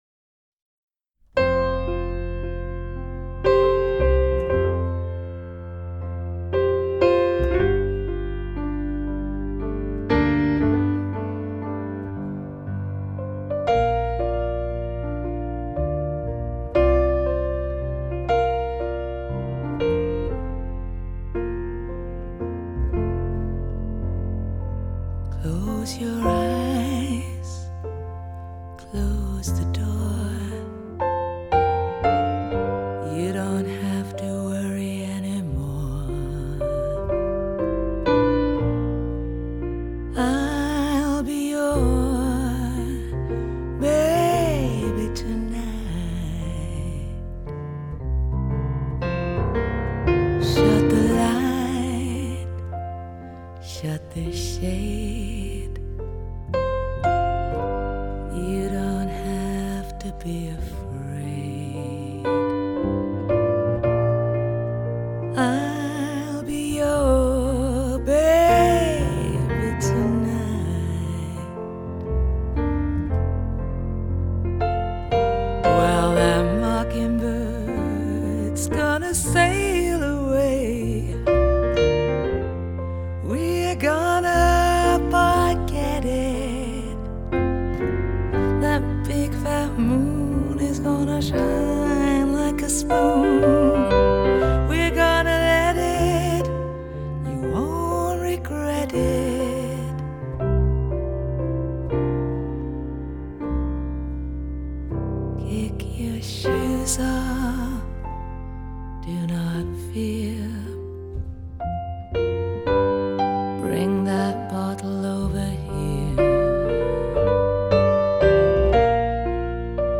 用她稍微低沉沙哑的声音